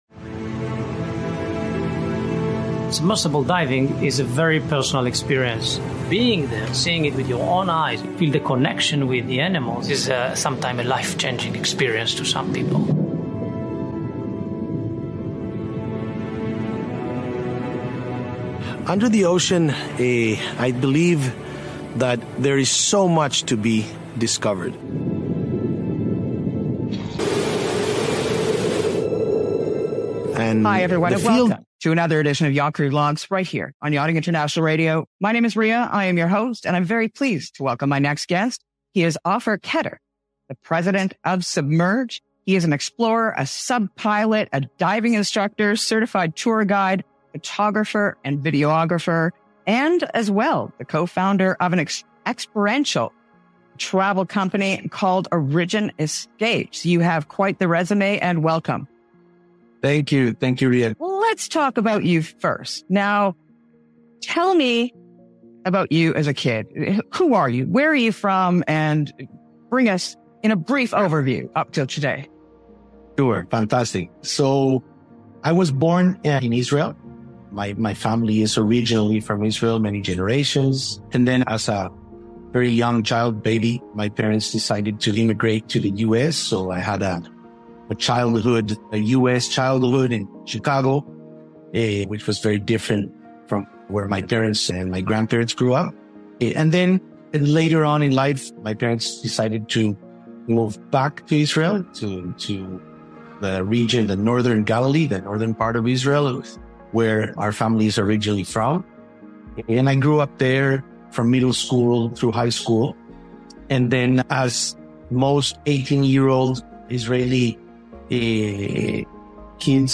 Don't miss this thrilling conversation that's redefining the future of underwater exploration!